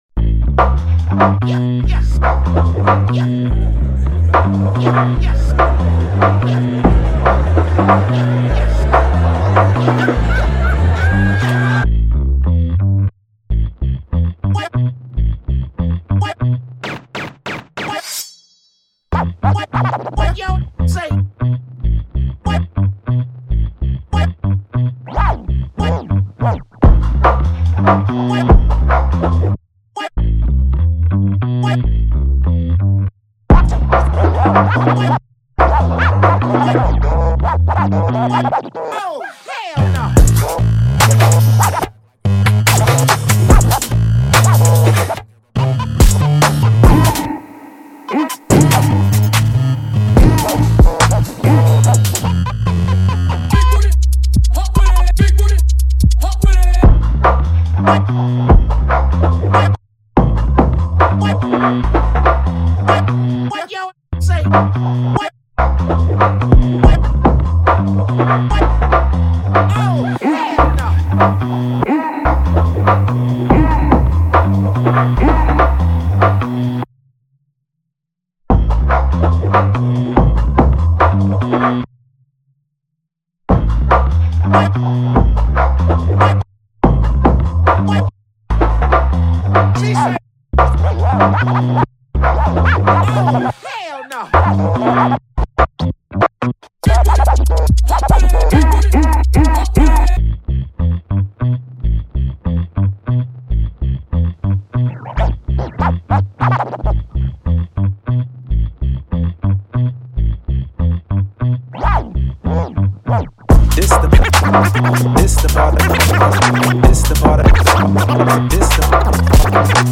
Hip-Hop Instrumentals